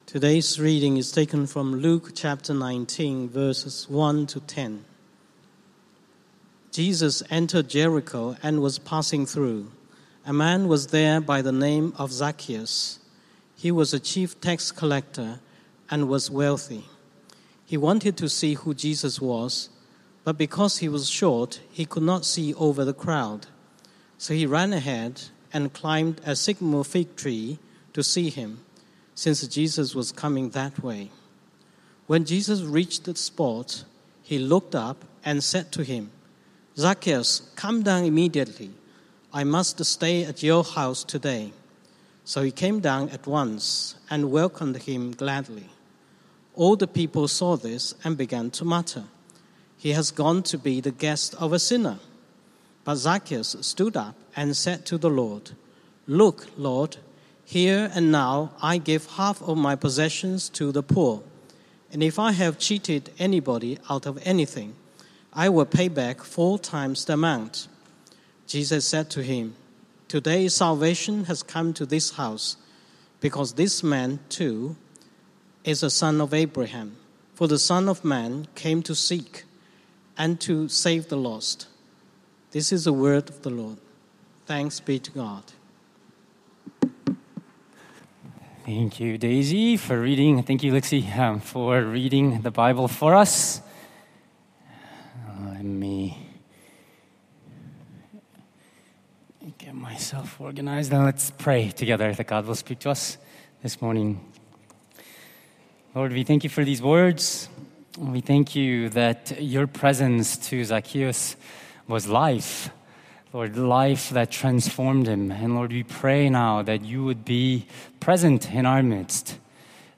Sermons Loading…